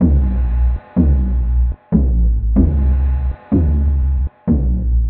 描述：砰砰砰 砰砰砰
Tag: 94 bpm Heavy Metal Loops Percussion Loops 957.49 KB wav Key : Unknown